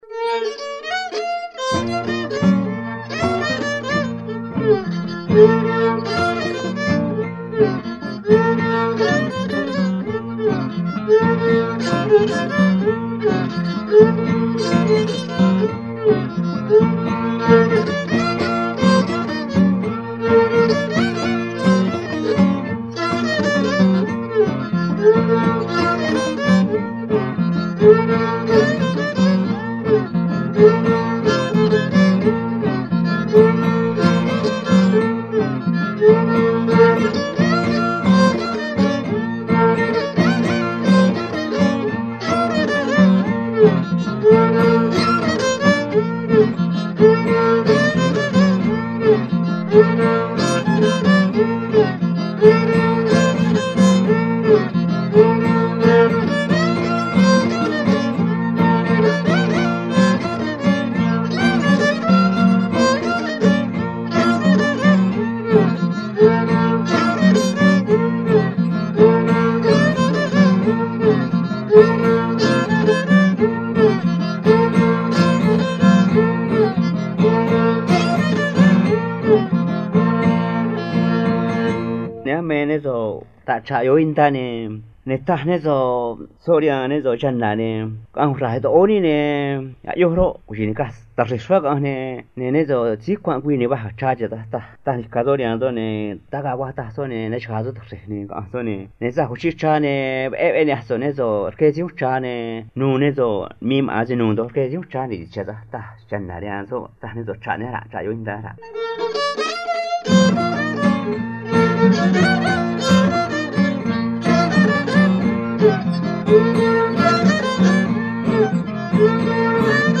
Música Tradicional Triqui